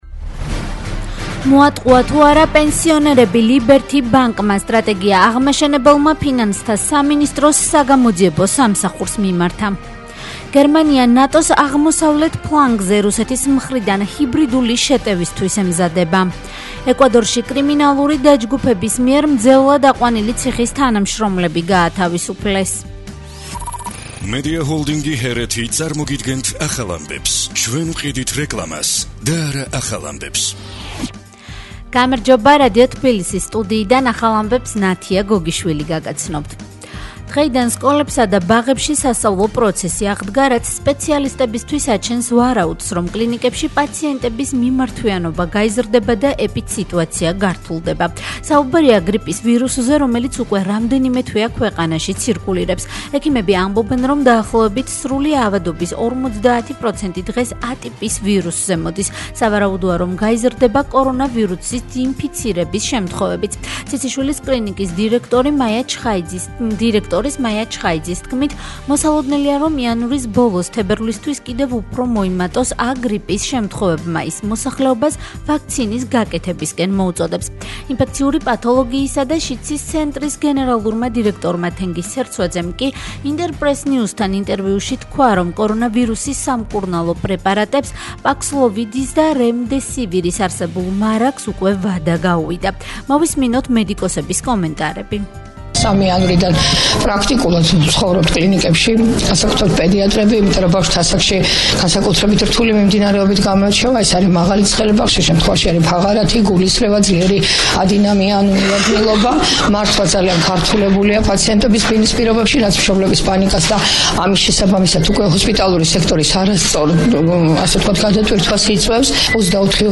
ახალი ამბები 13:00 საათზე